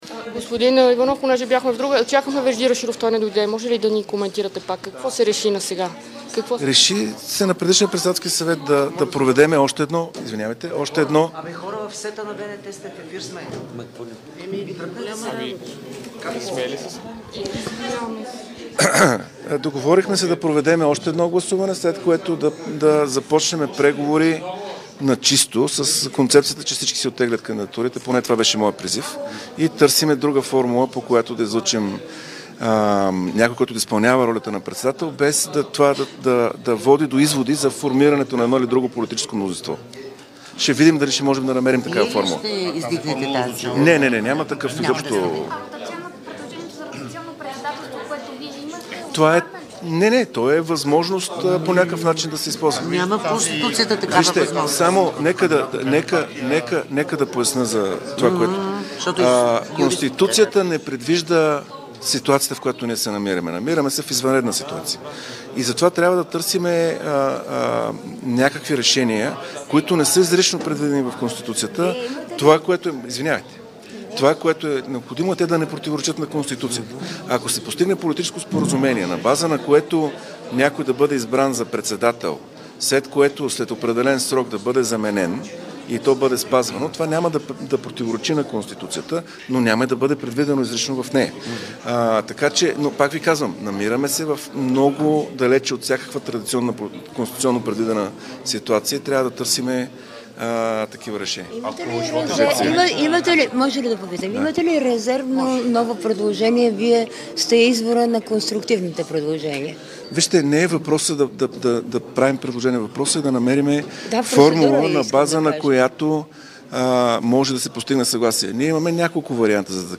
Briefing Hristo Ivanov 10 30H 20 10 22